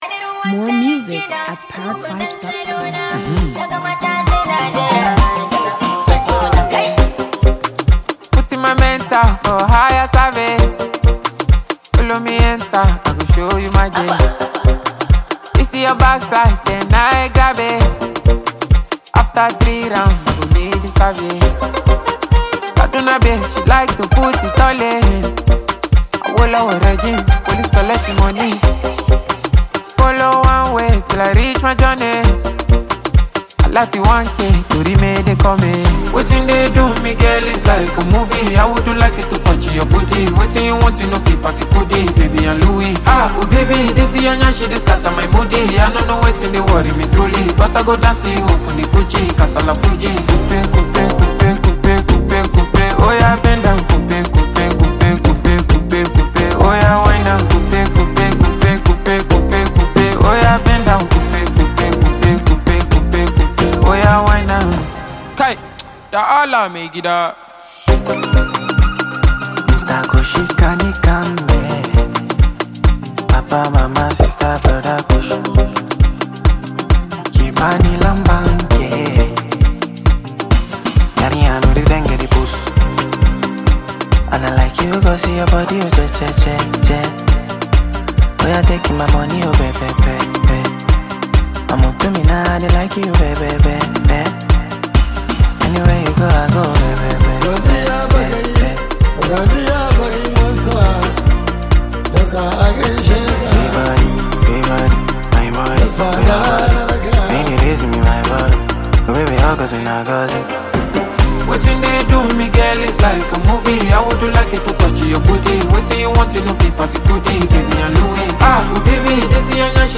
sweet-sounding new song